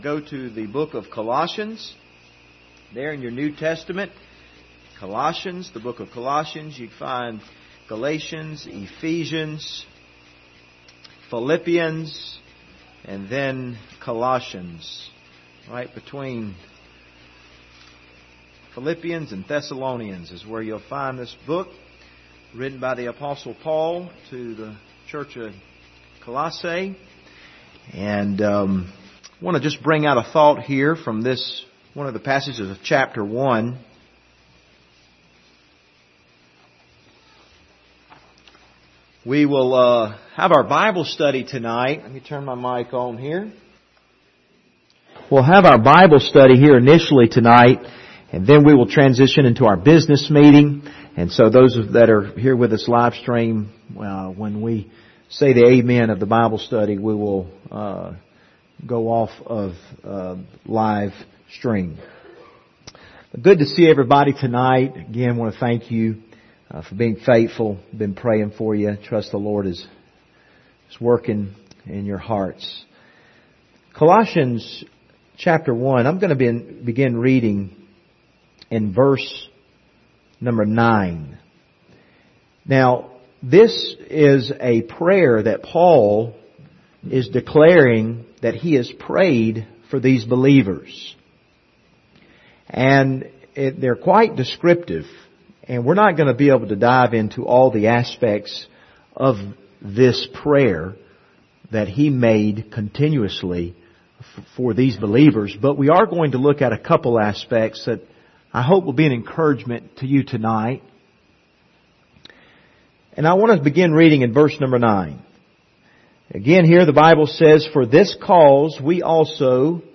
Passage: Colossians 1: 9-11 Service Type: Wednesday Evening